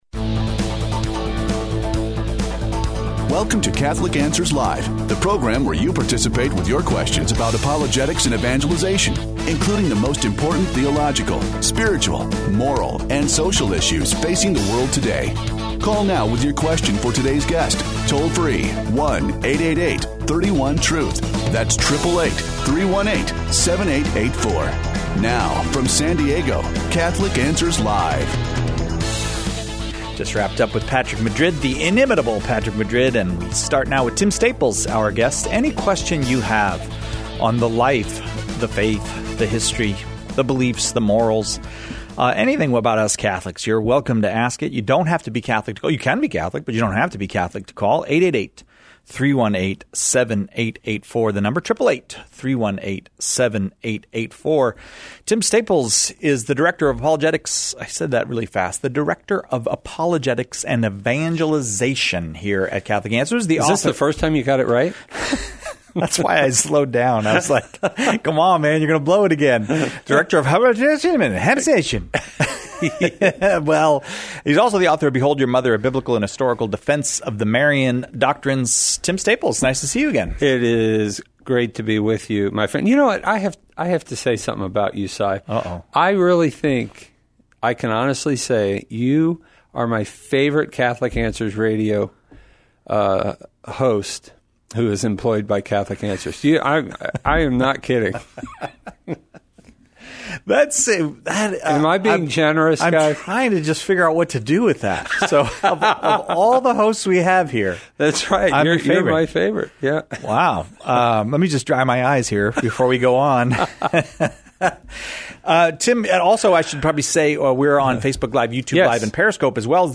The callers choose the topics during Open Forum, peppering our guests with questions on every aspect of Catholic life and faith, the moral life, and even philos...